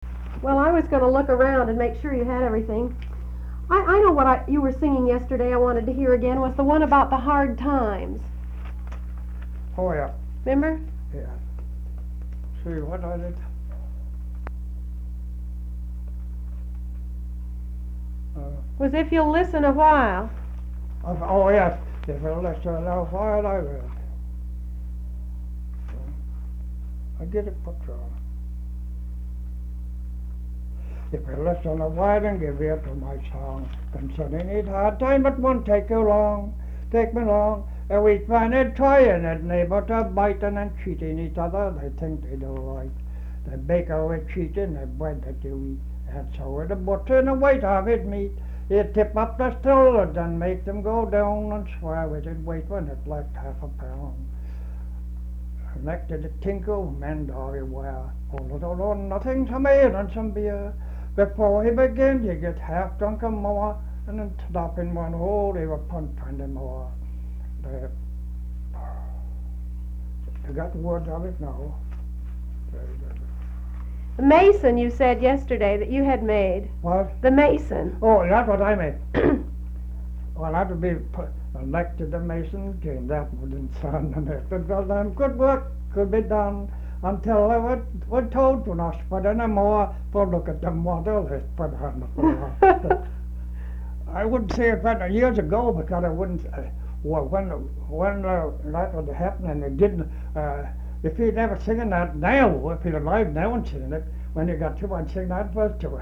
Folk songs, English--Vermont
sound tape reel (analog)
Marlboro, Vermont